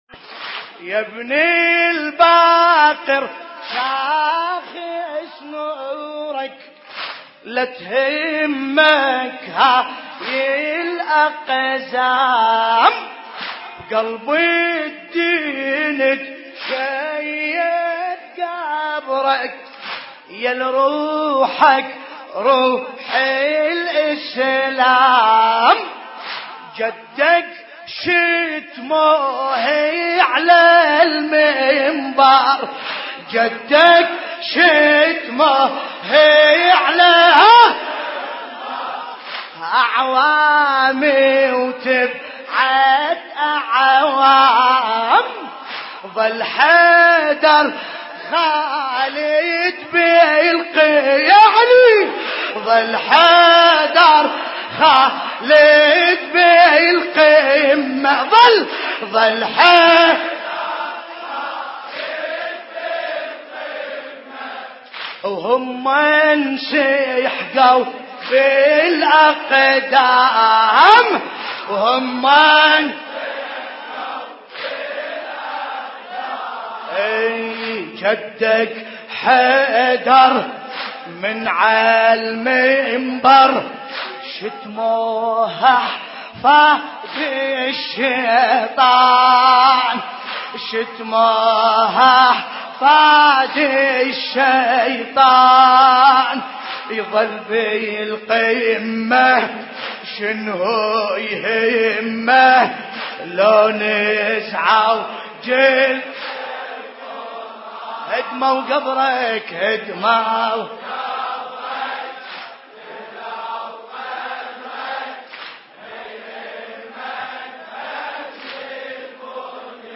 مراثي الامام الباقر (ع)